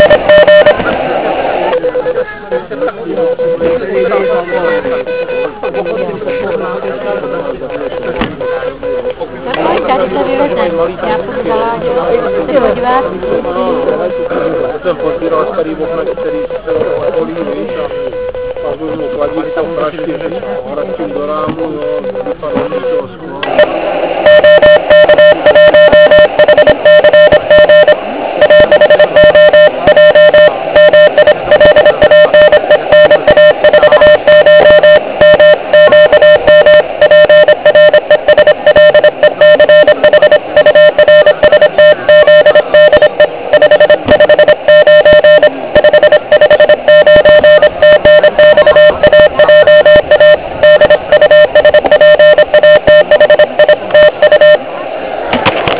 Jak sami z nahrávek můžete zjistit, byl operátor "zahlušován" dalšími zvuky HI.